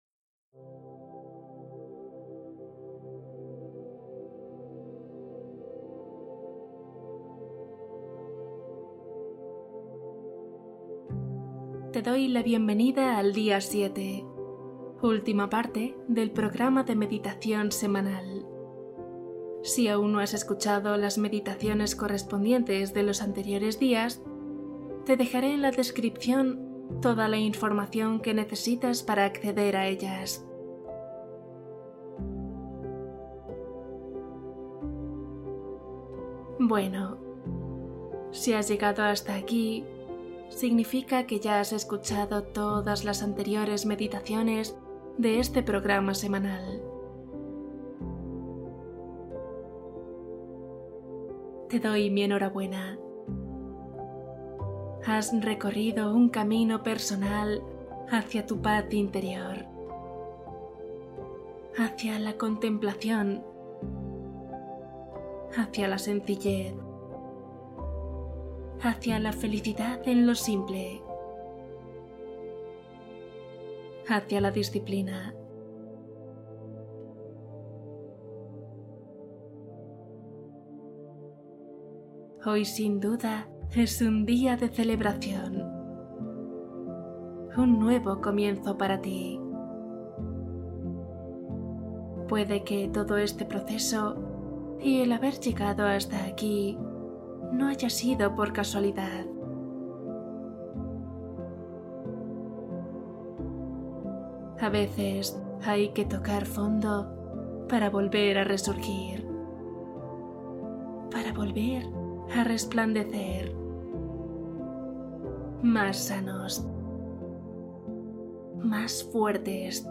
Cierre, renacer y gratitud | Meditación guiada